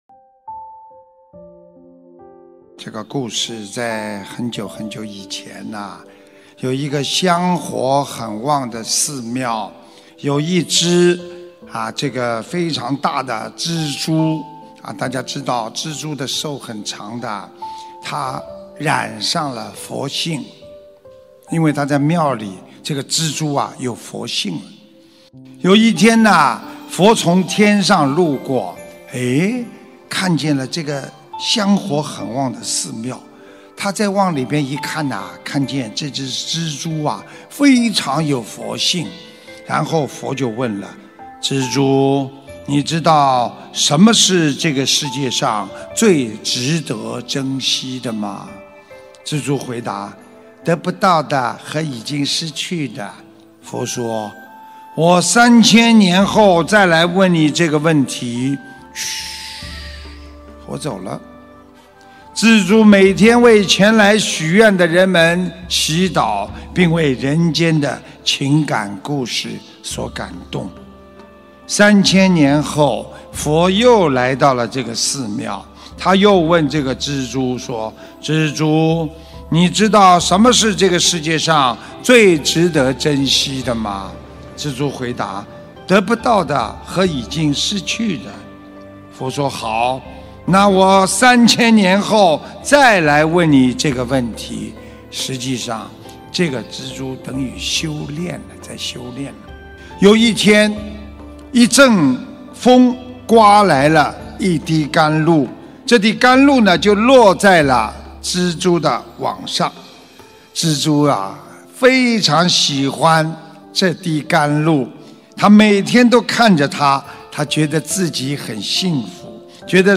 音频：千年的缘分再相遇你还认得我吗？什么是世上最值得珍惜的东西？2019年9月9日阿姆斯特丹法会师父开示！